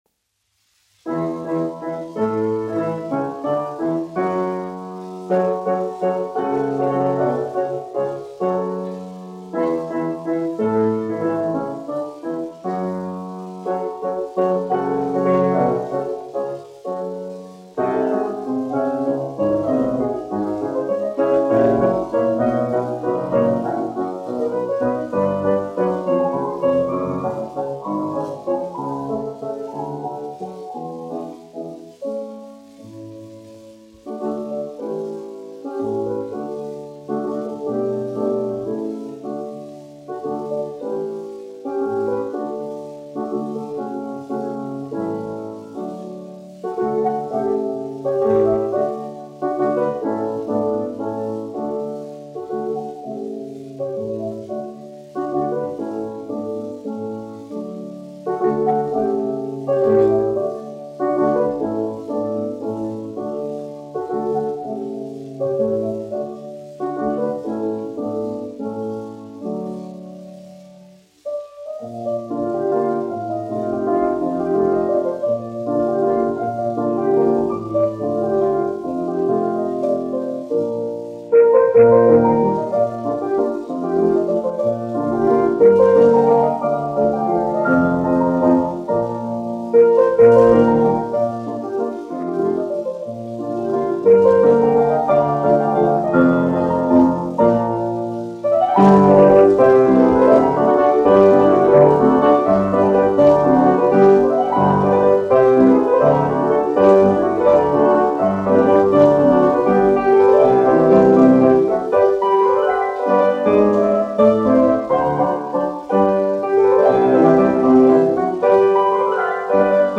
1 skpl. : analogs, 78 apgr/min, mono ; 25 cm
Klavieru mūzika
Latvijas vēsturiskie šellaka skaņuplašu ieraksti (Kolekcija)